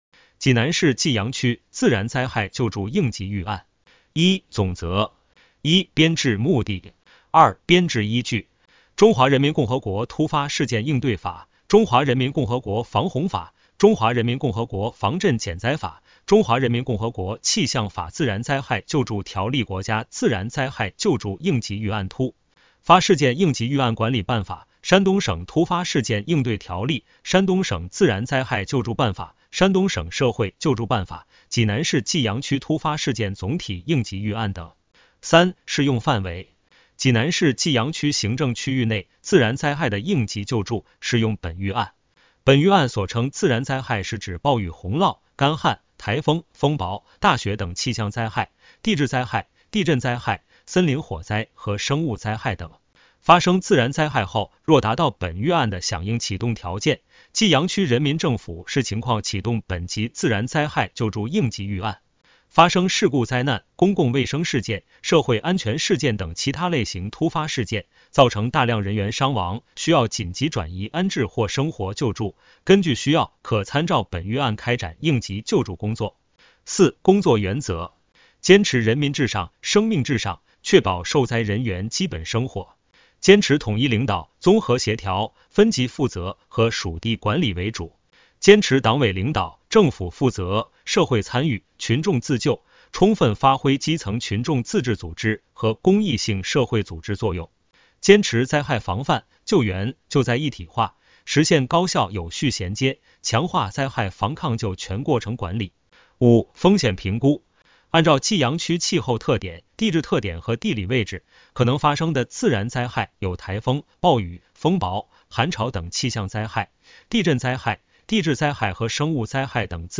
【有声朗读】《济南市济阳区自然灾害救助应急预案》